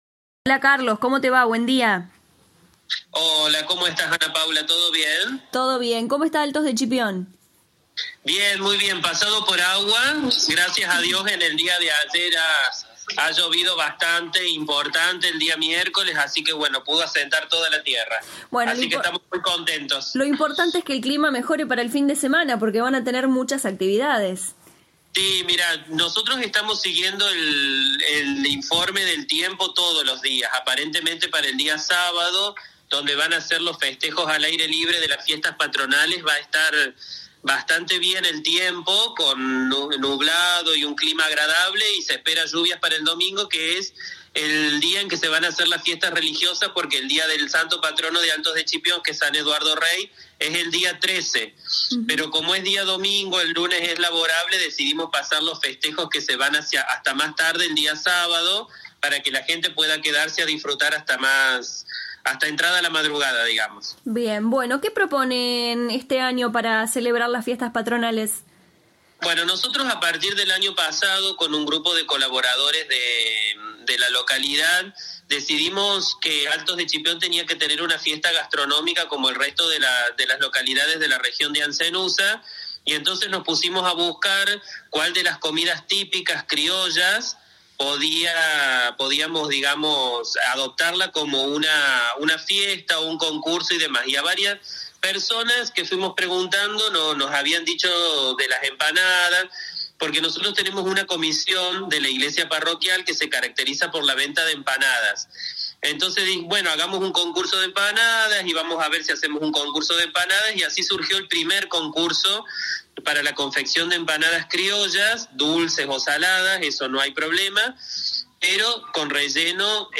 En diálogo con LA RADIO 102.9 FM el intendente de Altos de Chipión Carlos Fuyana destacó que con diversas actividades celebrarán a su Santo Patrono.
ENTREVISTA CON EL INTENDENTE: